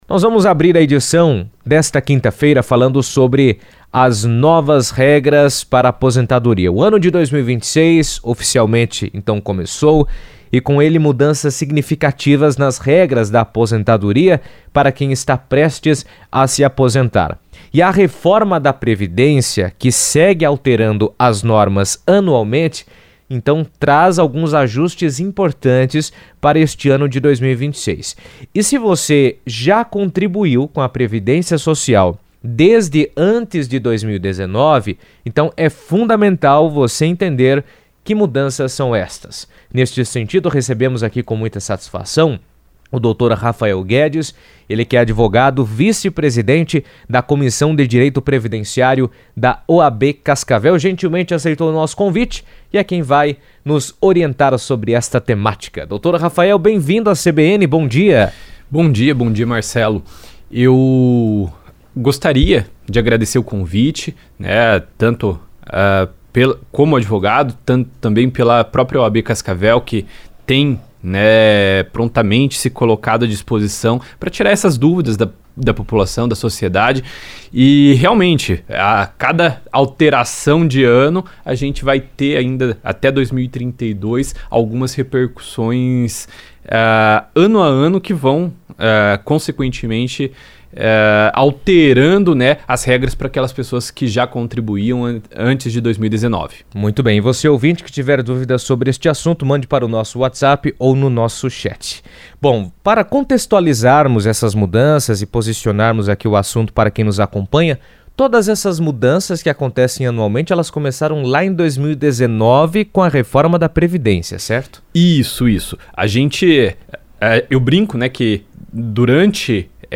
Em 2026, entram em vigor mudanças significativas nas regras de aposentadoria, afetando idade mínima, tempo de contribuição e sistema de pontos para quem contribui ao INSS. Durante entrevista à CBN